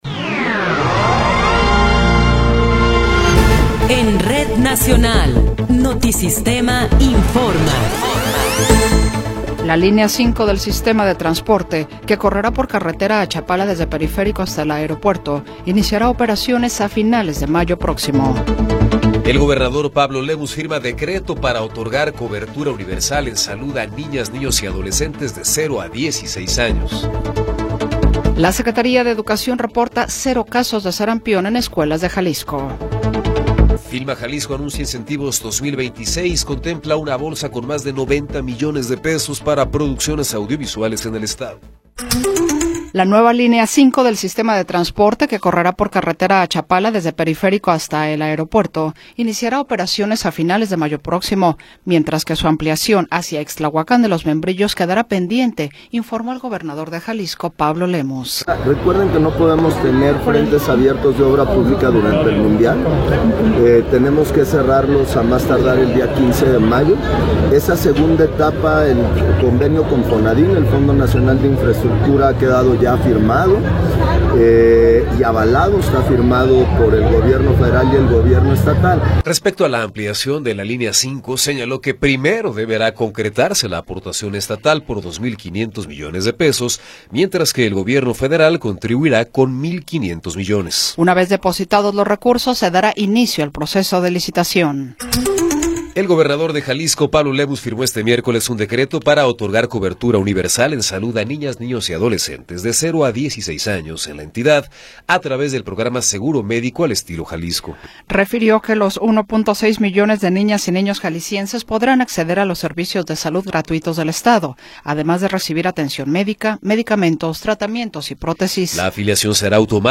Noticiero 14 hrs. – 22 de Abril de 2026
Resumen informativo Notisistema, la mejor y más completa información cada hora en la hora.